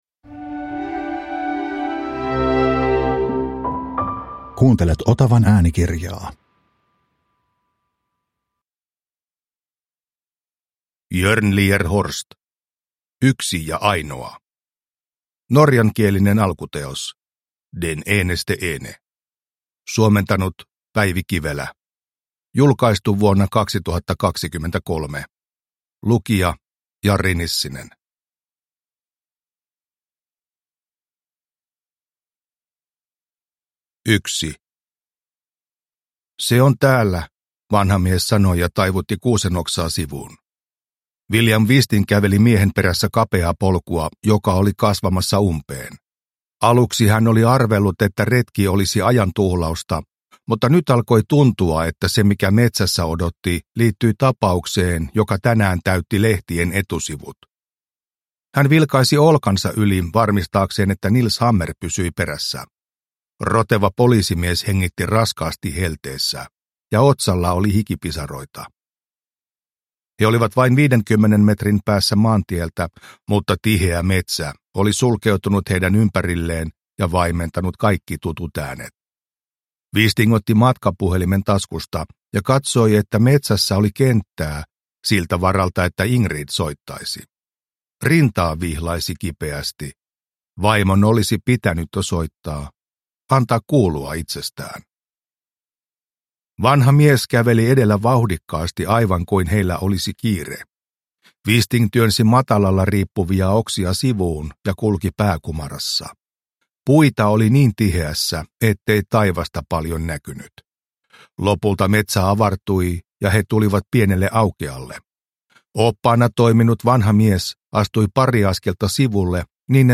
Yksi ja ainoa – Ljudbok – Laddas ner